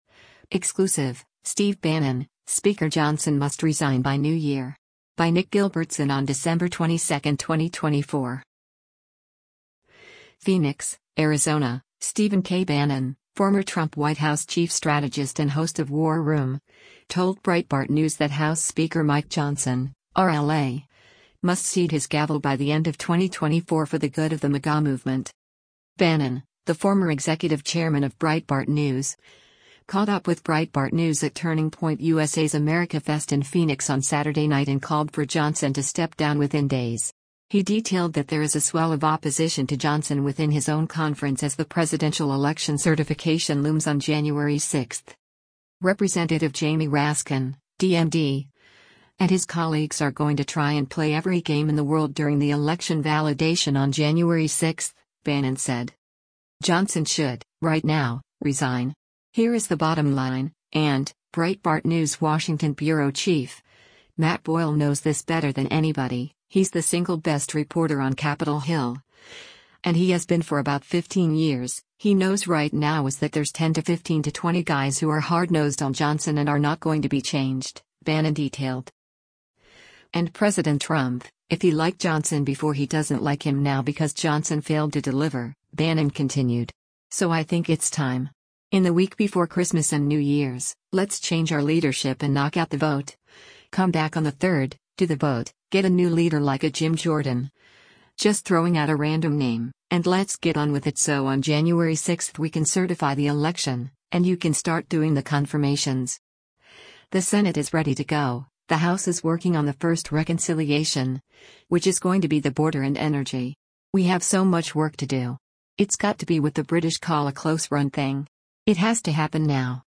Bannon, the former executive chairman of Breitbart News, caught up with Breitbart News at Turning Point USA’s AmericaFest in Phoenix on Saturday night and called for Johnson to step down within days.